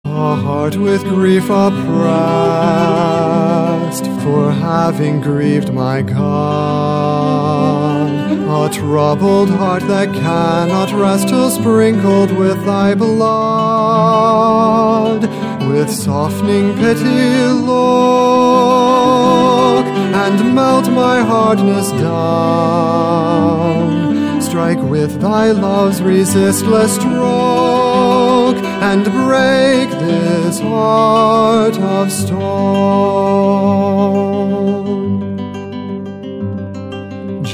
Songs for Voice & Guitar